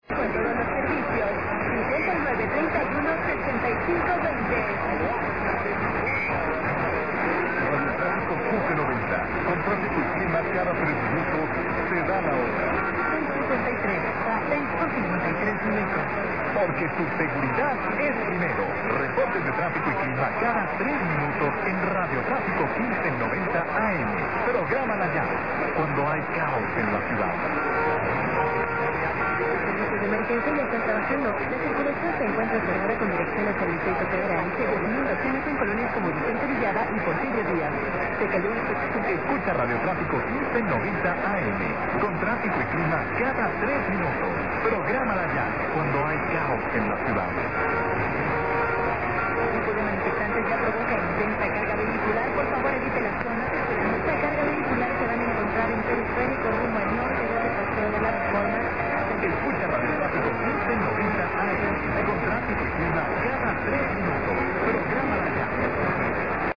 It sounds like Radio Trafico.
> Several times last night I was hearing a station on 1590 with Spanish
> programming (only talk when I heard it), calling itself "Radio Clasico 1590